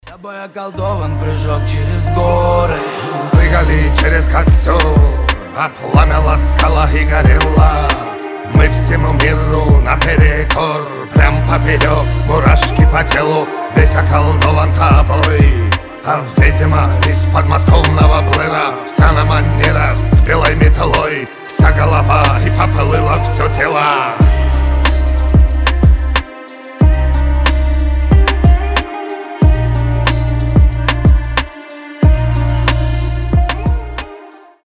рэп
атмосферные , дуэт